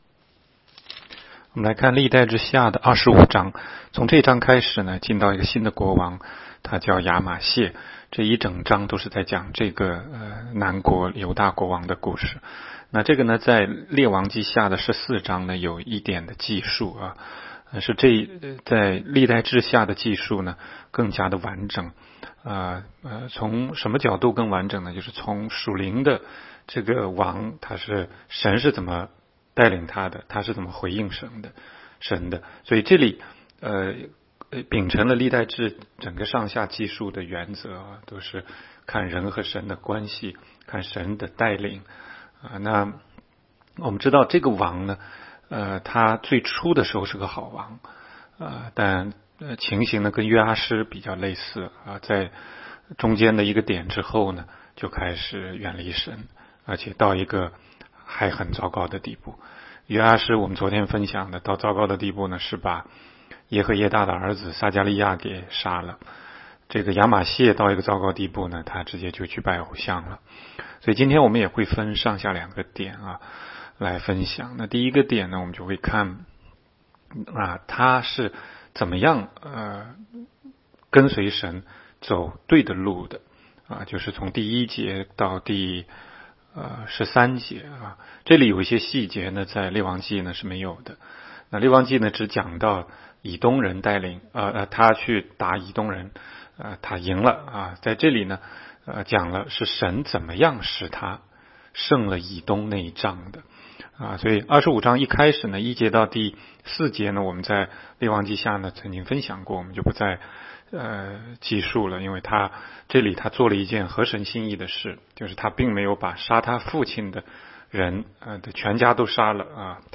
16街讲道录音 - 每日读经-《历代志下》25章